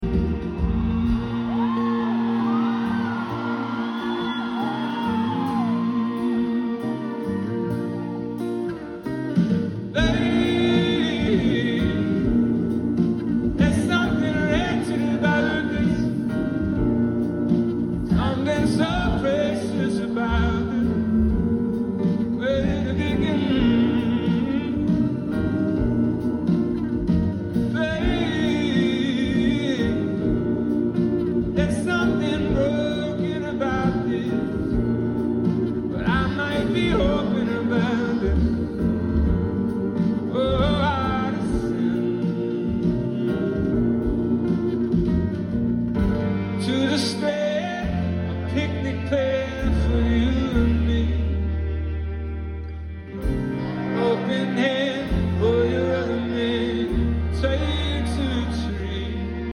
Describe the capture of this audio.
slowed down version